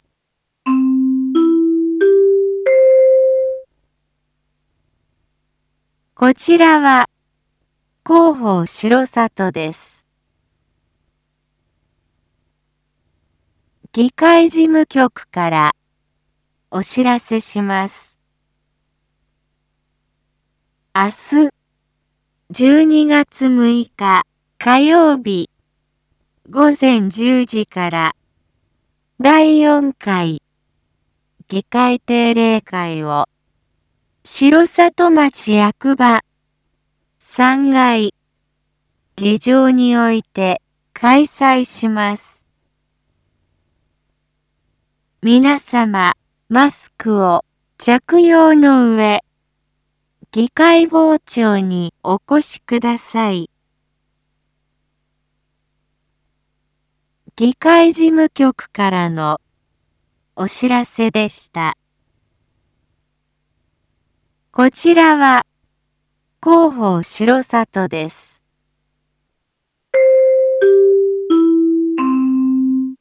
Back Home 一般放送情報 音声放送 再生 一般放送情報 登録日時：2022-12-05 19:01:14 タイトル：R4.12.5 19時放送分 インフォメーション：こちらは広報しろさとです。